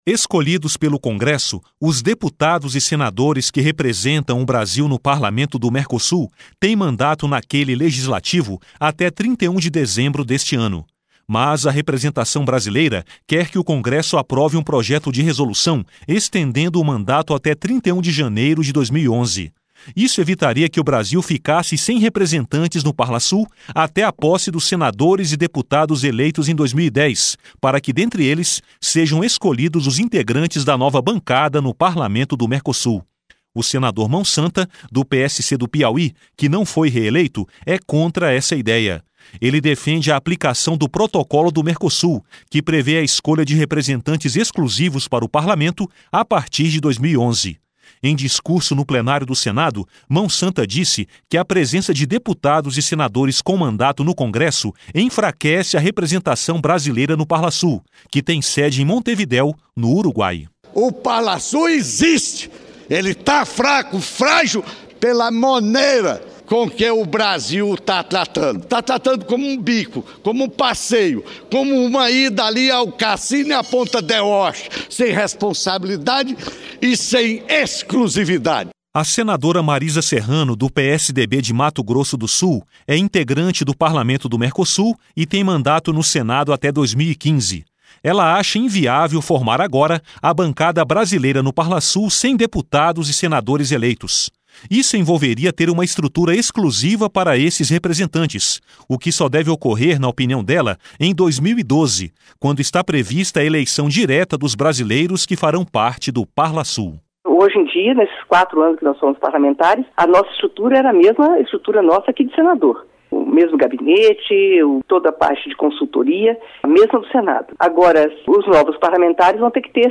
Ele defende a aplicação do Protocolo do Mercosul, que prevê a escolha de representantes exclusivos para o Parlamento a partir de 2011. Em discurso no Plenário do Senado, Mão Santa disse que a presença de deputados e senadores com mandato no Congresso enfraquece a representação brasileira no Parlasul, que tem sede em Montevidéu, no Uruguai.